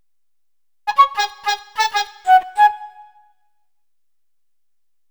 Ridin_ Dubs - Flute.wav